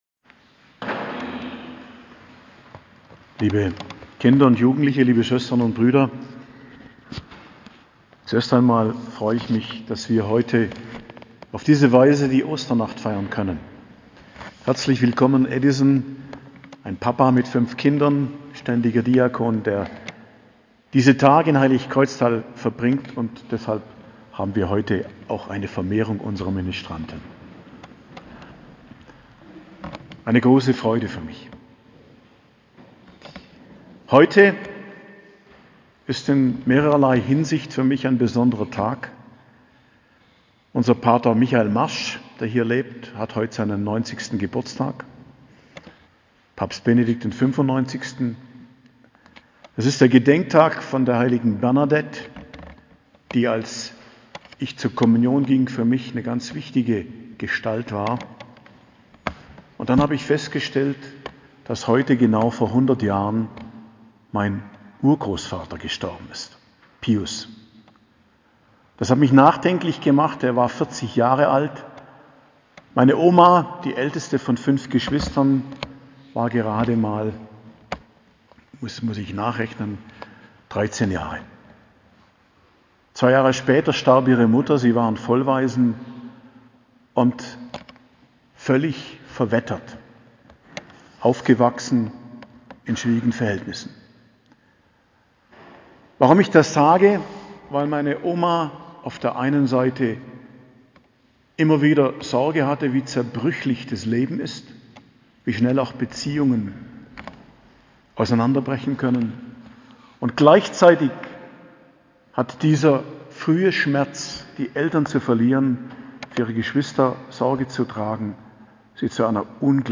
Predigt zur Feier der Osternacht, 16.04.2022 ~ Geistliches Zentrum Kloster Heiligkreuztal Podcast